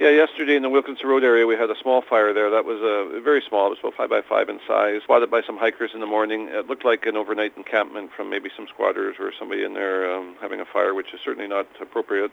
He says there was also a second, smaller fire on Sunday.